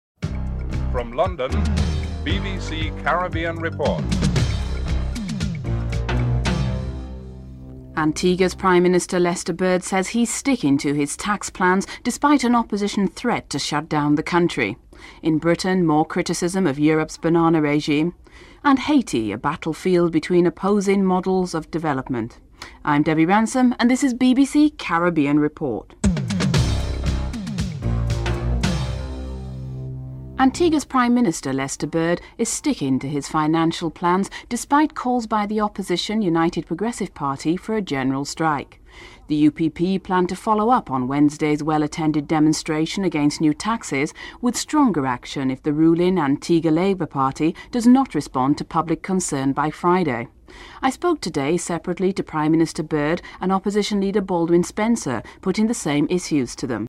3. Interview with Prime Minister Lester Bird and Opposition Leader Baldwin Spencer on the introduction of new tax plans (01:04-05:31)